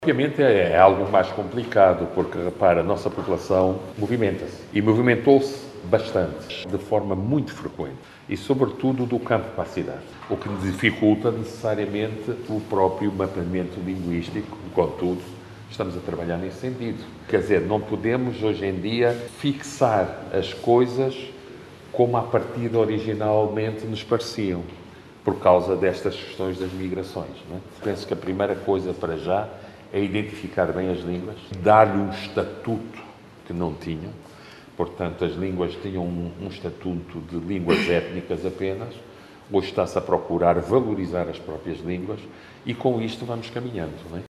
Mas ainda assim, o Ministro da Cultura Filipe Zau diz que o trabalho vai ser concluído, sem, no entanto, definir um horizonte temporal.